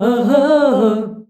AHAAH D.wav